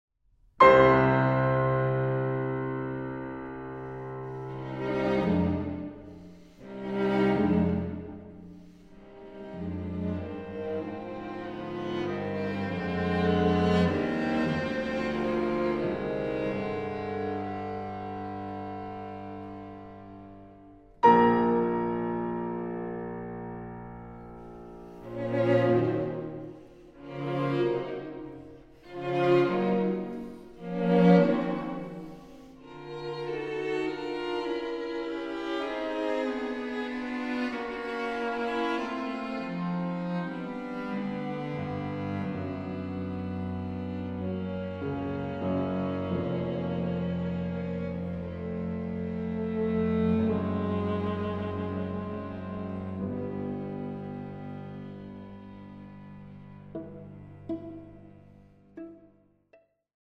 Piano quartet